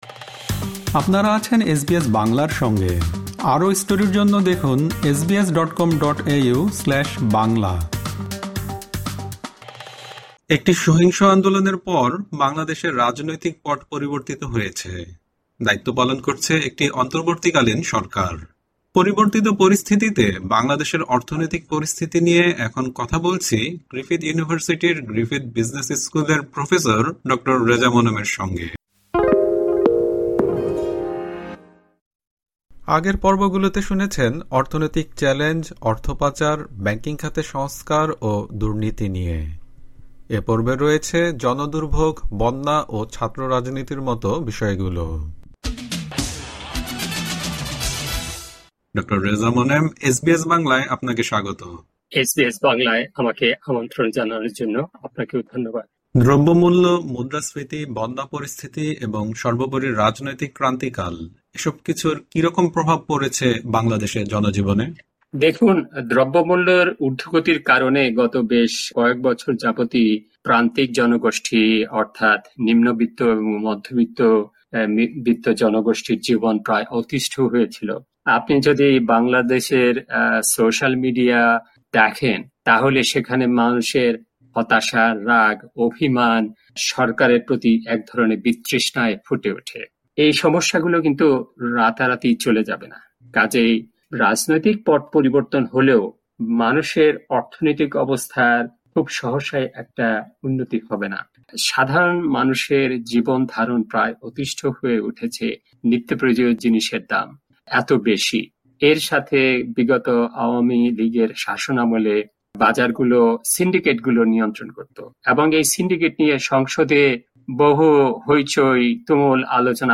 তার সাক্ষাৎকারের এই (তৃতীয়) পর্বে রয়েছে জনদুর্ভোগ, বন্যা ও শিক্ষাঙ্গনে রাজনীতি নিয়ে আলোচনা।